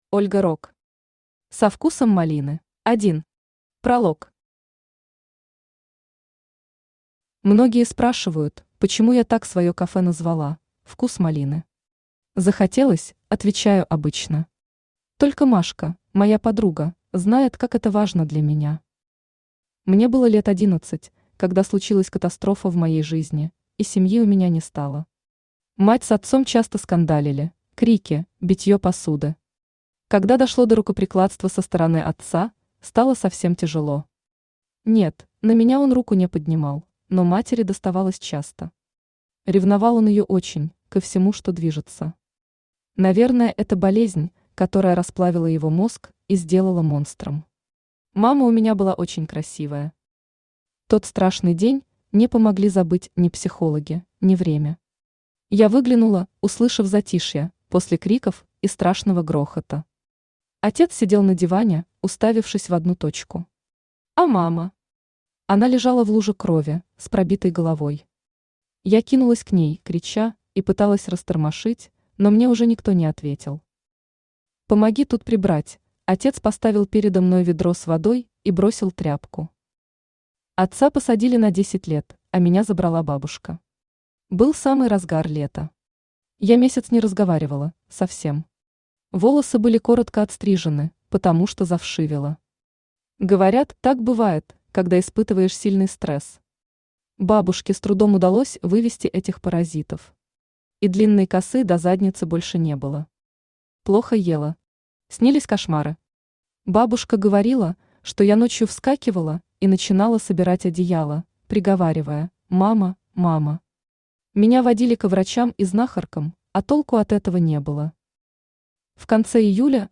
Аудиокнига Со вкусом малины | Библиотека аудиокниг
Aудиокнига Со вкусом малины Автор Ольга Рог Читает аудиокнигу Авточтец ЛитРес.